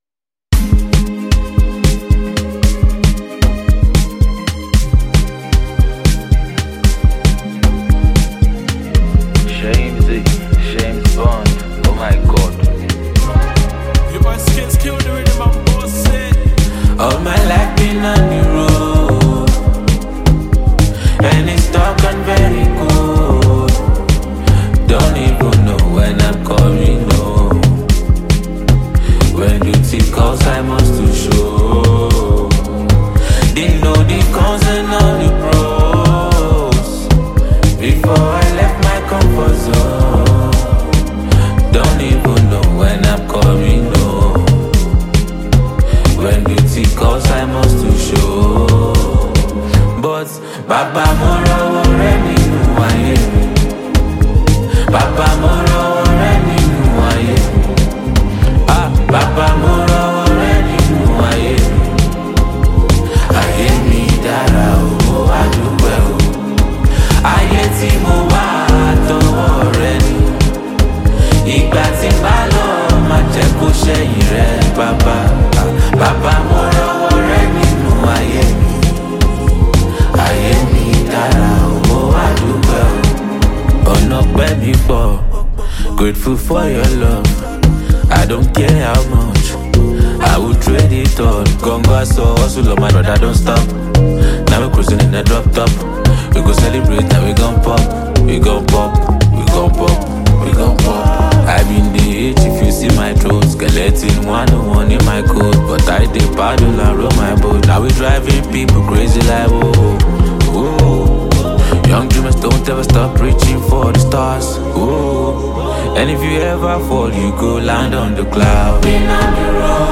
highly skilled Nigerian rapper and singer
spine-tingling tune